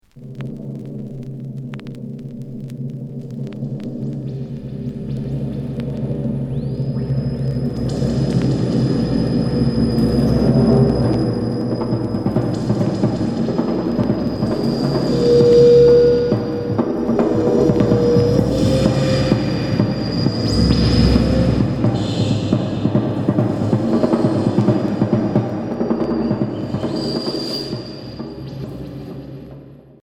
Expérimental psychédélique